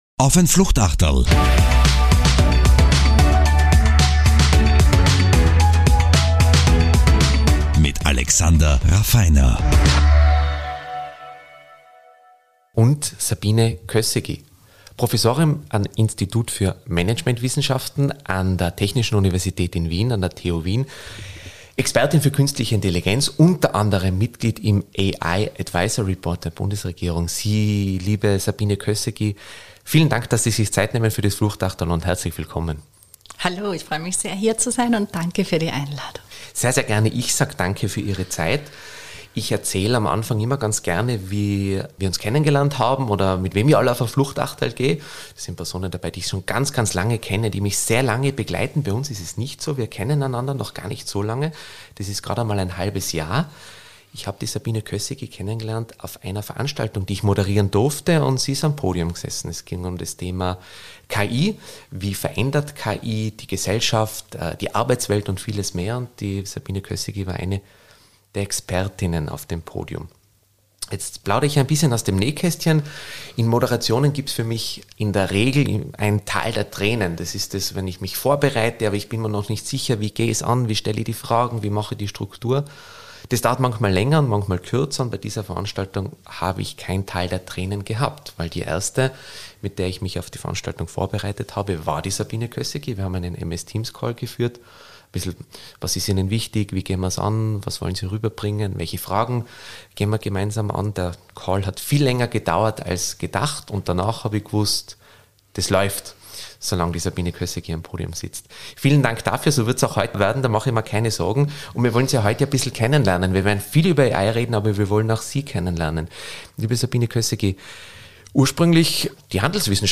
für dieses anregende und angenehme Gespräche...und gelacht haben wir übrigens auch.